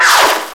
THNDR3.WAV